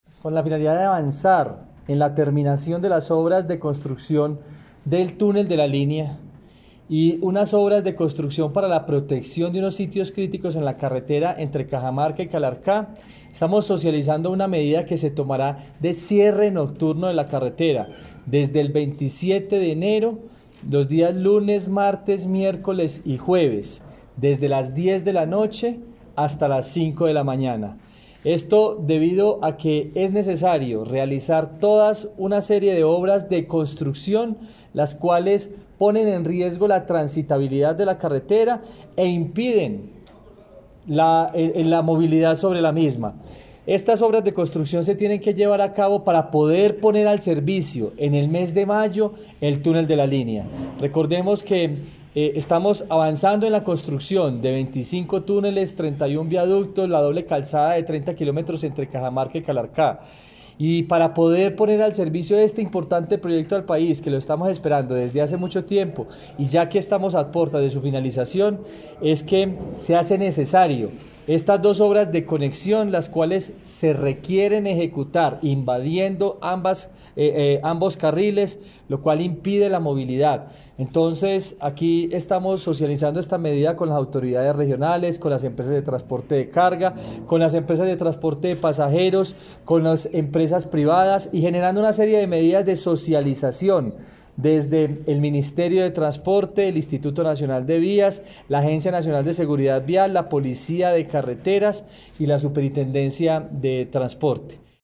Audios Director General del INVÍAS, Juan Esteban Gil Chavarría